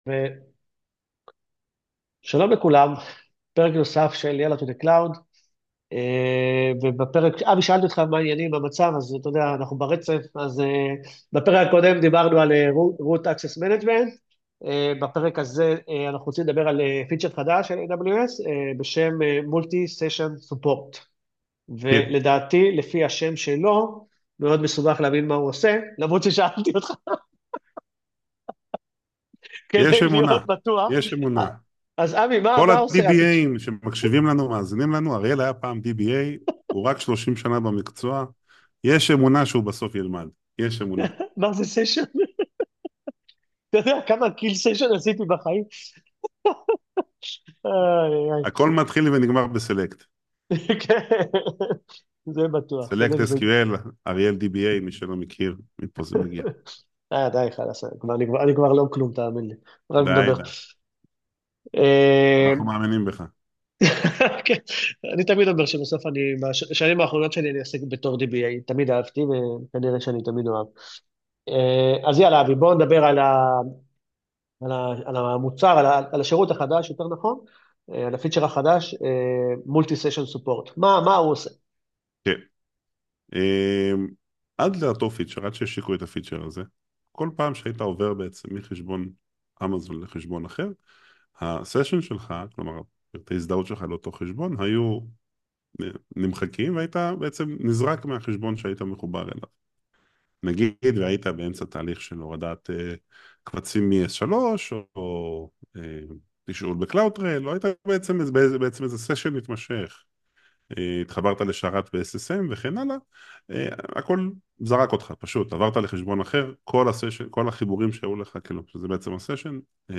הפרק כולל לייב דמו.